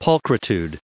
added pronounciation and merriam webster audio
635_pulchritudinous.ogg